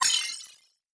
Hi Tech Alert 5.wav